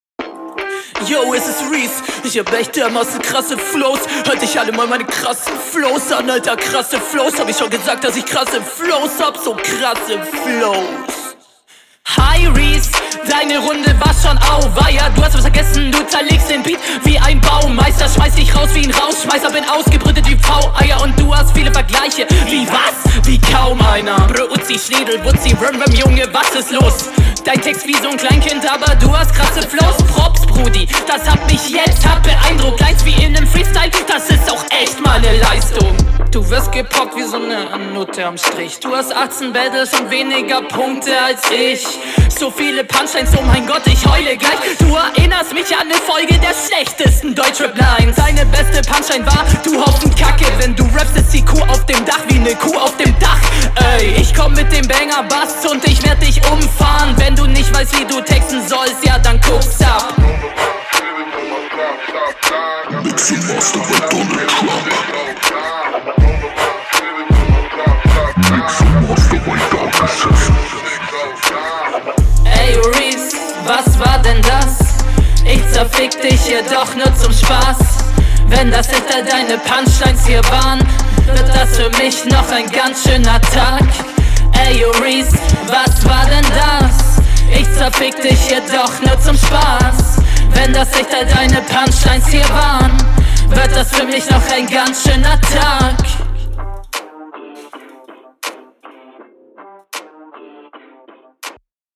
Ich weiß nicht warum aber das Intro hat mich mega gekillt.
Flow: Flowlich nicht übel hättest aber mehr machen müssen um an die Vorgabe ranzukommen Text: …